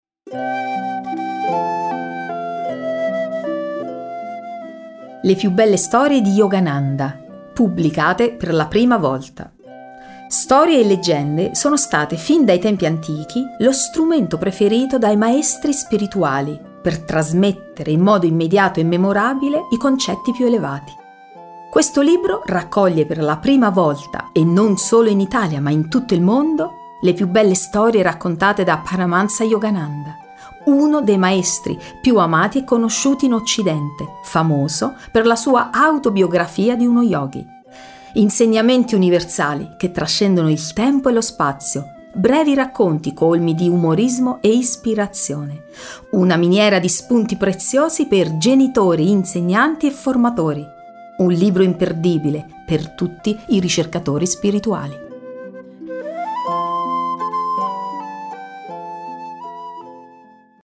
audiolibro scaricabile